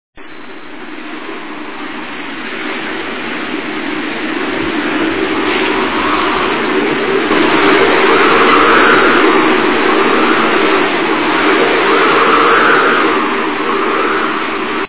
ulular del viento de tormenta.
Vientotormenta.wav